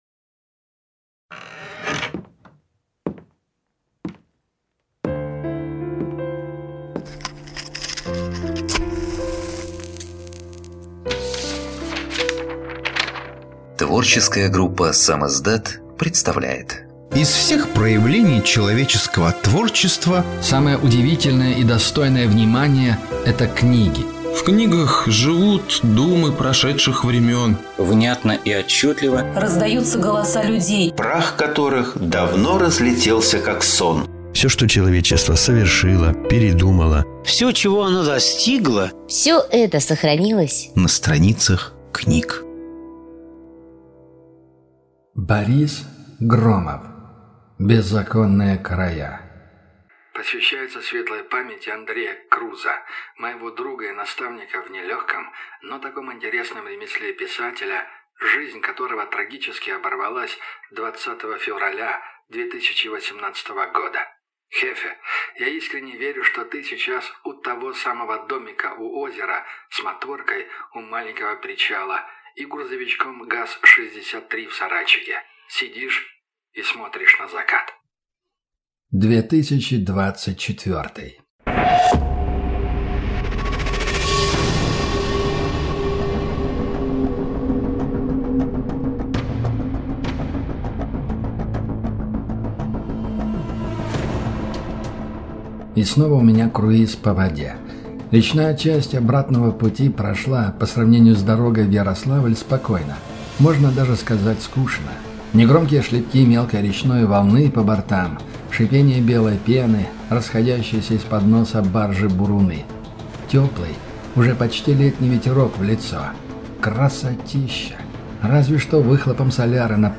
Аудиокнига Беззаконные края | Библиотека аудиокниг
Прослушать и бесплатно скачать фрагмент аудиокниги